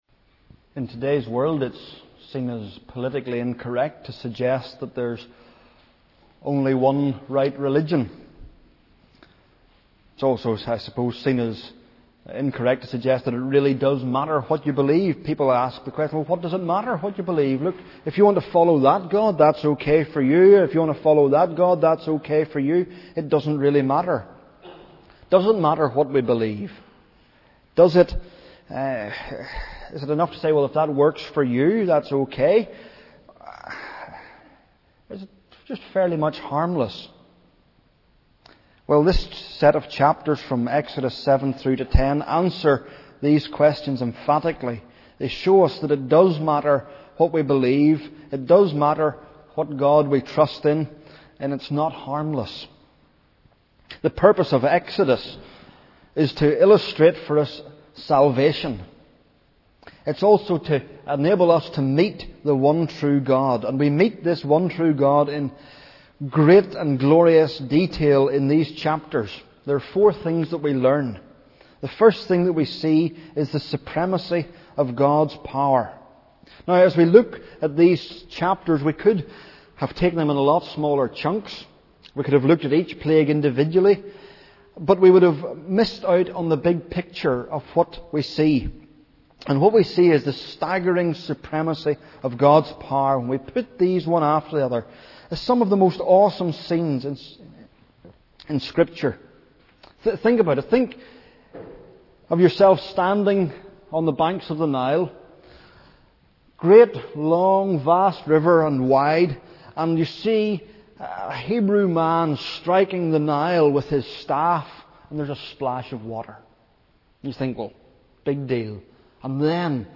Sermons at New Life Fellowship